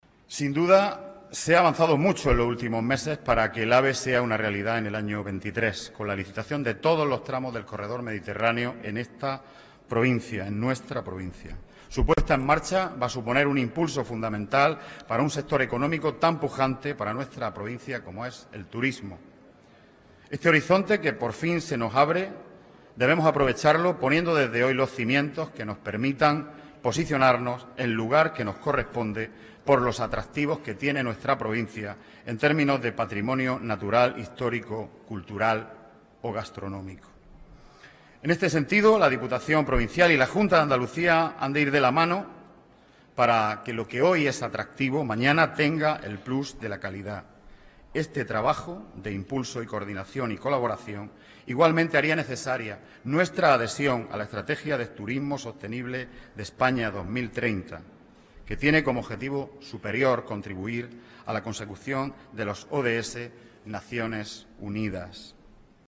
Así lo ha manifestado el portavoz socialista en la institución provincial, Juan Antonio Lorenzo, durante el pleno de constitución de la nueva corporación, en la que el PSOE contará con 10 diputados.